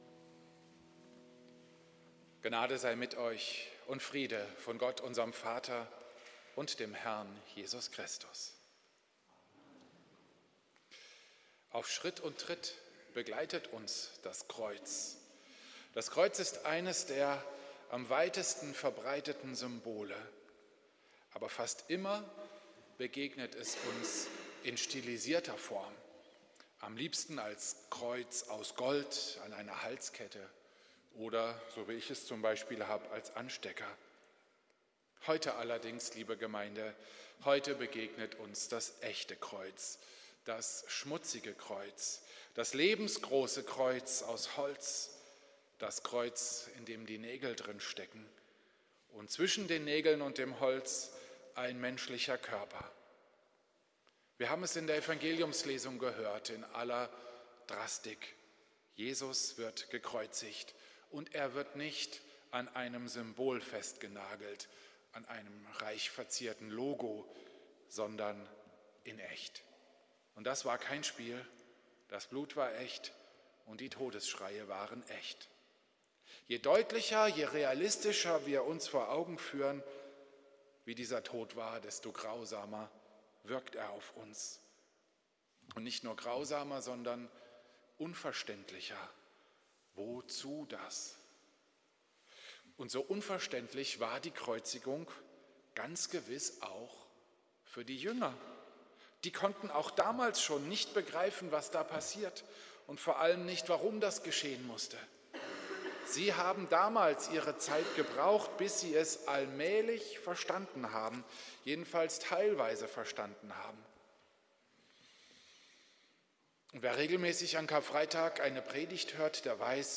Karfreitag-Predigt zur Kreuzigung Jesu.